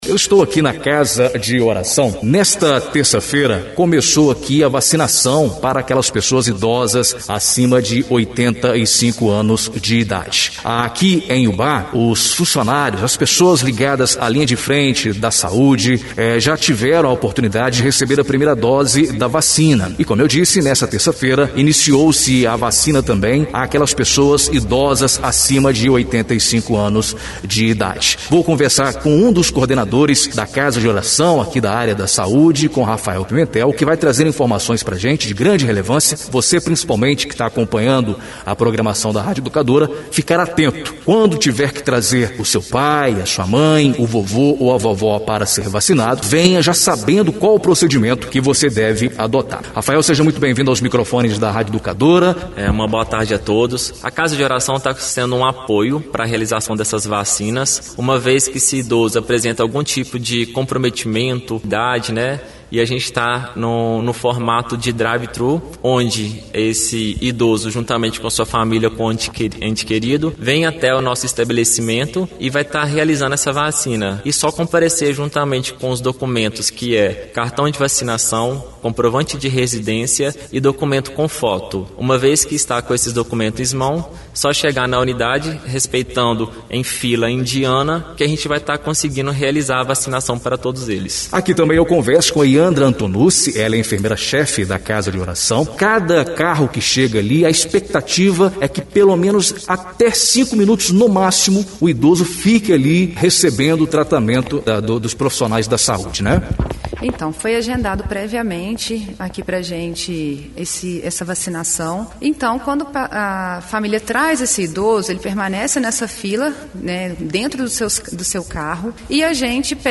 Entrevista exibida na Rádio Educadora AM/FM Ubá-MG